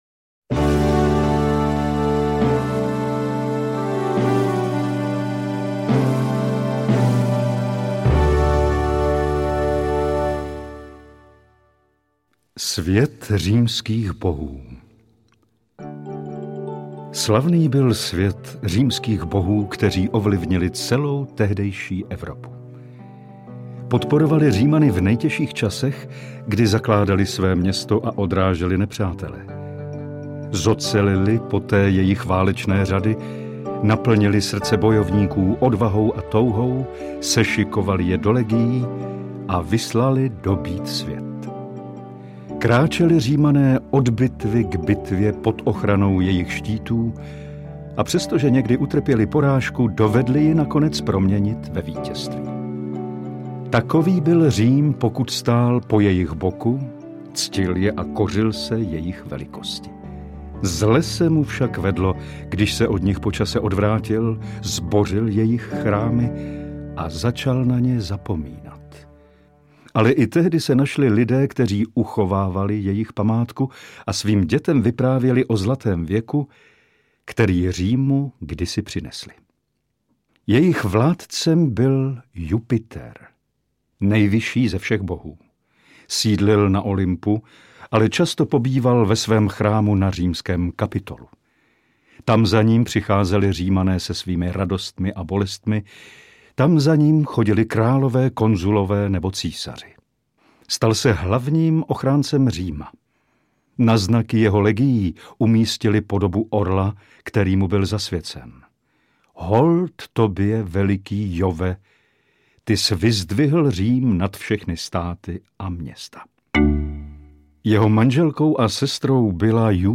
Tři herečtí představitelé mužných typů a majitelé krásných hlasů jsou ideálními průvodci světem legendárních hrdinů a heroických mýtů. Tvůrci audioknihy využili pro větší dramatičnost a "akčnost" napínavých příběhů mnoho zvukových efektů, které poslechu dodají strhující spád.